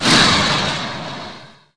missile5.mp3